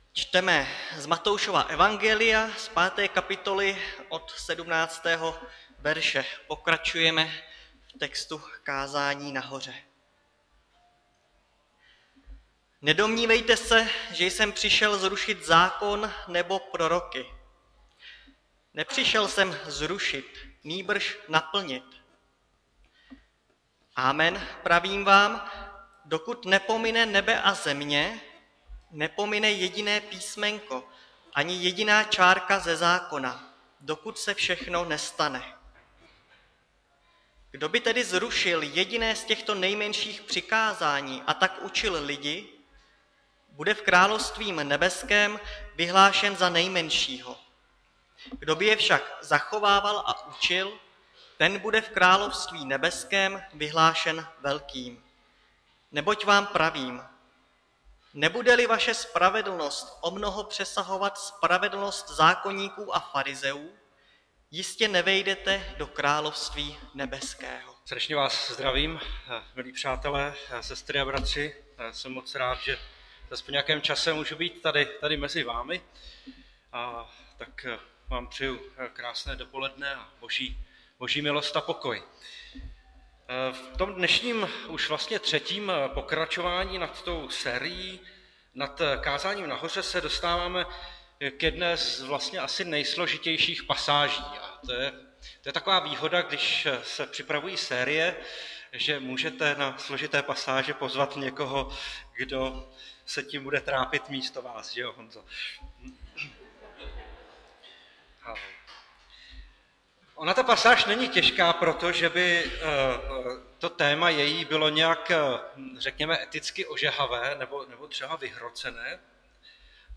Nedělní kázání 26.9.2021 – Vyšší spravedlnost